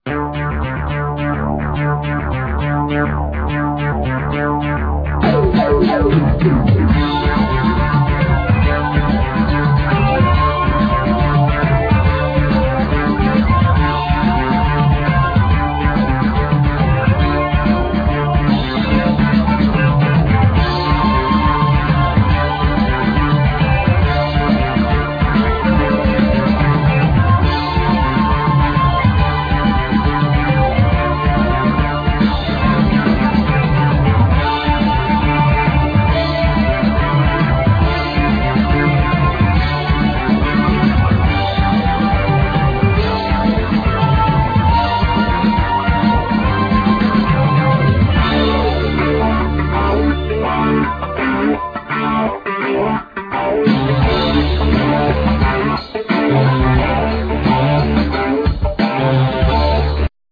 Micromoog, ARP Omni, Clavinet,Fender piano,Piano,Vocal
Ac. guitar,Micromoog, Ckavinet, Cello, Vocal
Micromoog, ARP Omni, Fender piano, Violin, Vocal
El. guitar, Vocal
Drums, Percussion, Micromoog, Vocal